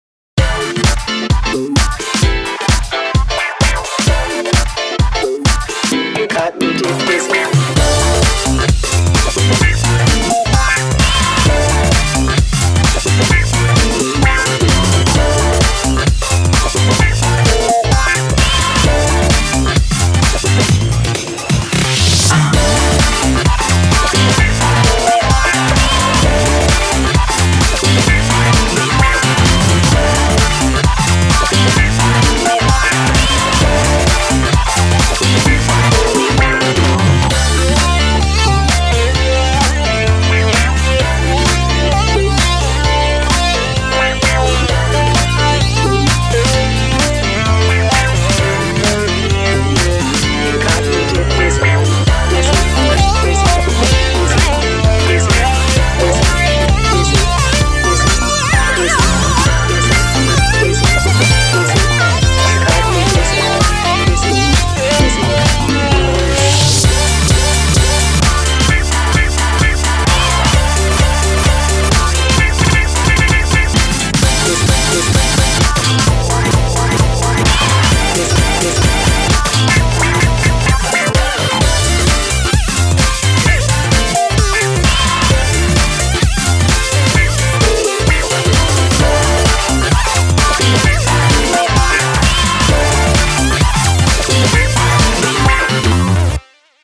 BPM130
Audio QualityPerfect (High Quality)
Cool funk/disco song